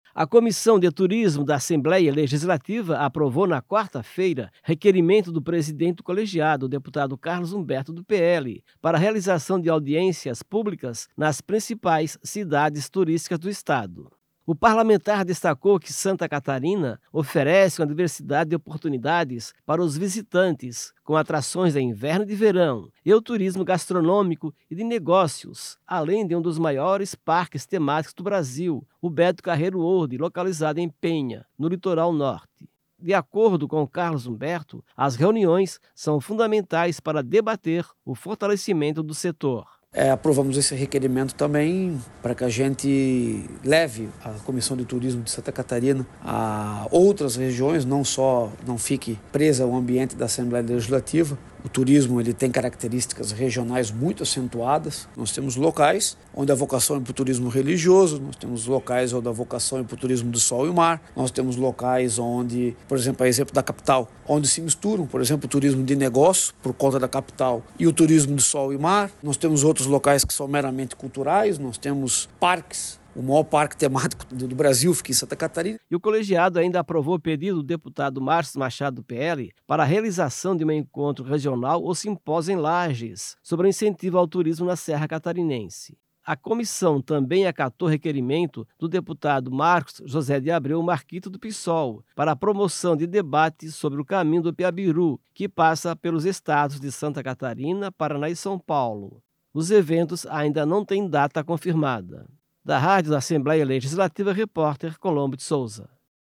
Entrevista com:
- deputado Carlos Humberto (PL).